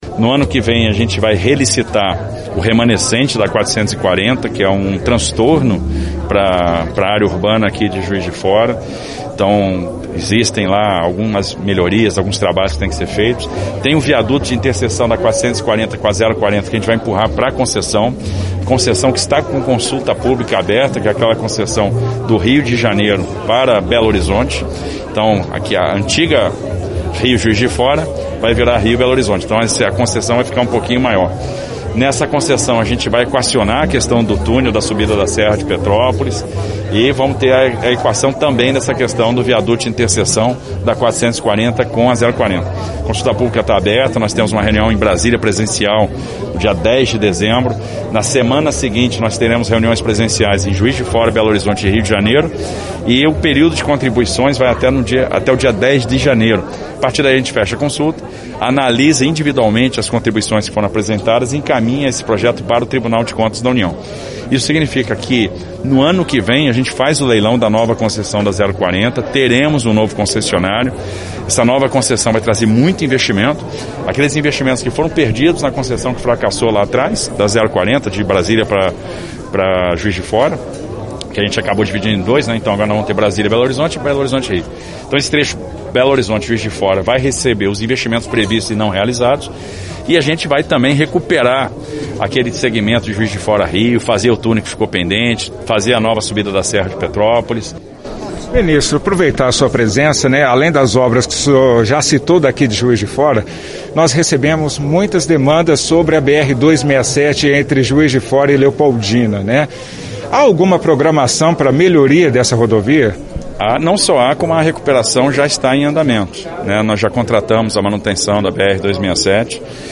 No evento, o ministro conversou com a imprensa e falou sobre as obras de mobilidade urbana feitas na cidade, sendo entregues seis das oito programadas, dentre viadutos e pontes.